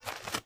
STEPS Dirt, Walk 29.wav